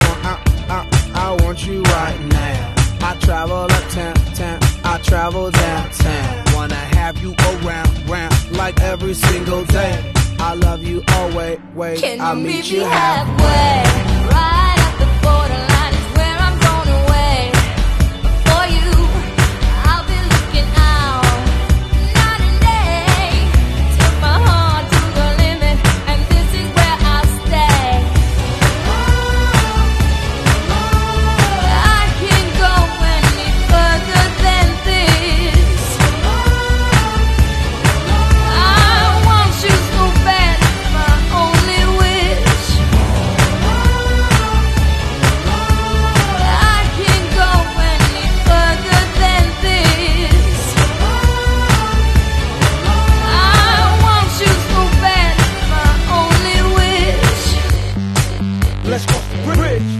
Part1 Of My Vocal Stims Sound Effects Free Download